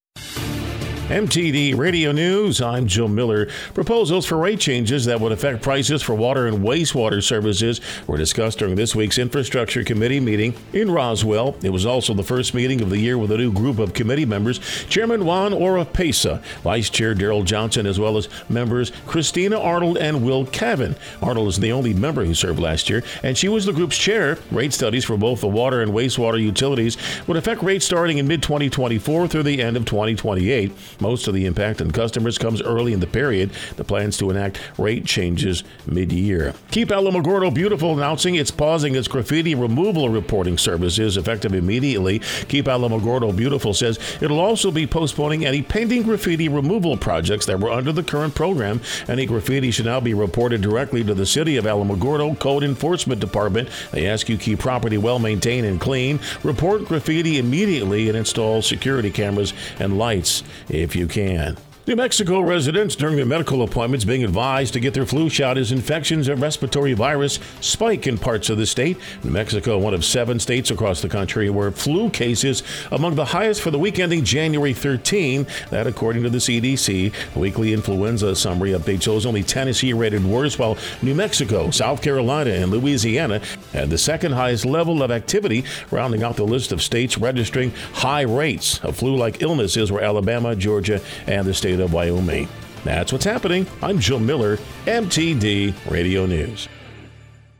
W105 NEWS JAN. 26, 2024